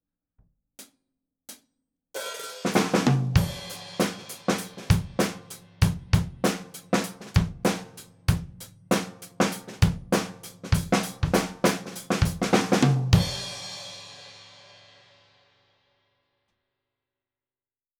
すべて、EQはしていません。
②アンビエンス　５０㎝
先ほどより、近づけてみました。バスドラムのちょっと前あたりです！
さっきより、タイコ類がはっきりと
聞こえるようになりましたね！
ドラム-アンビ-50㎝.wav